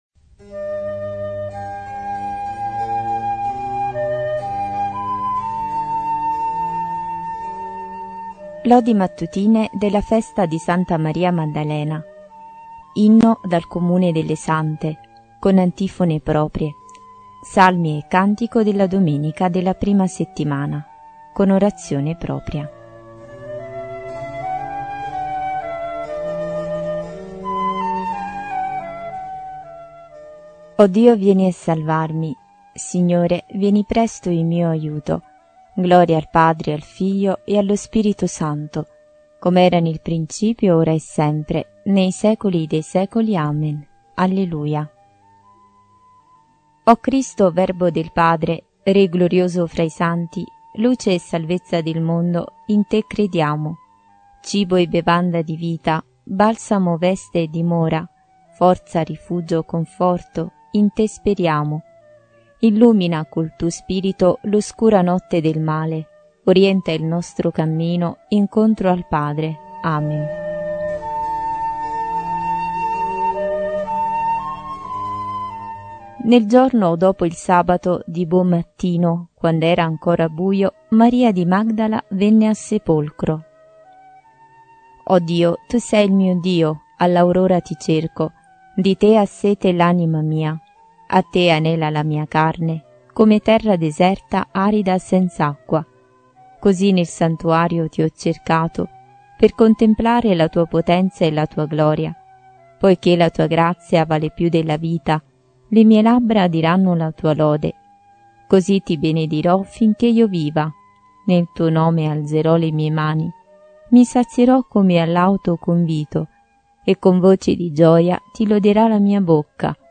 Liturgia delle Ore